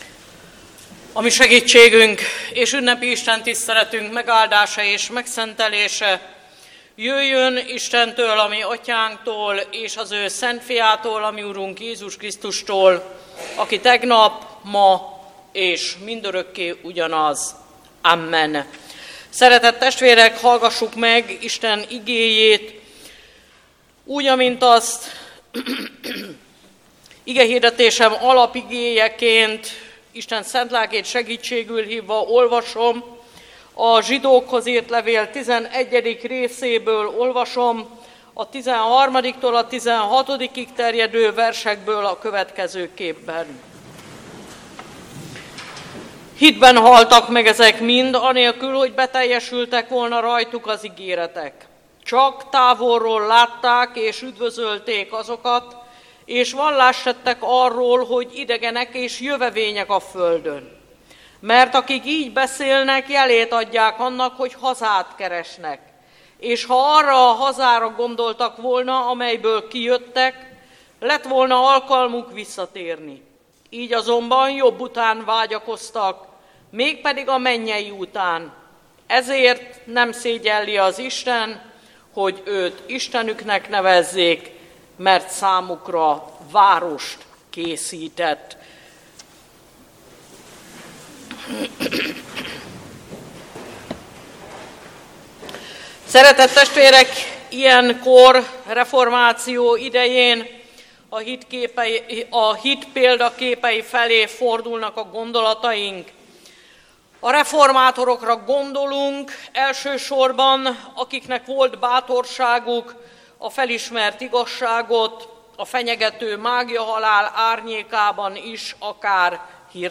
REFORMÁCIÓ ÜNNEPE - Más alapot senki sem vethet a meglévőn kívül, amely a Jézus Krisztus.